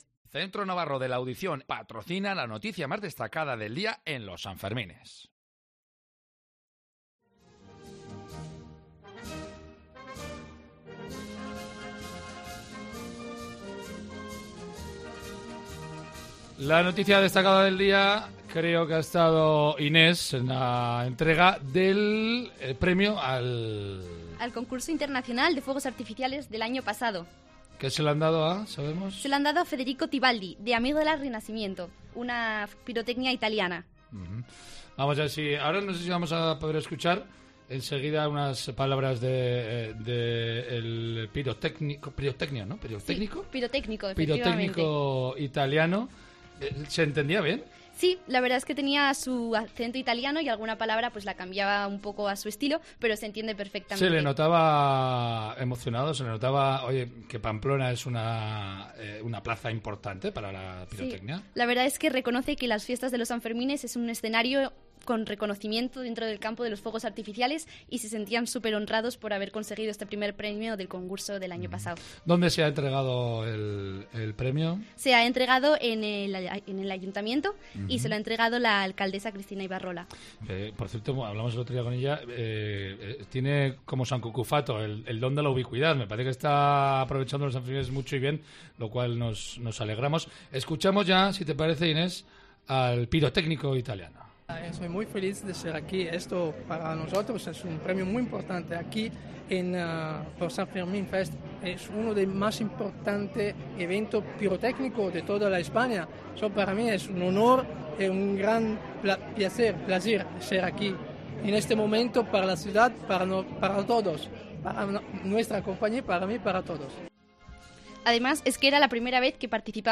FUEGOS ARTIFICIALES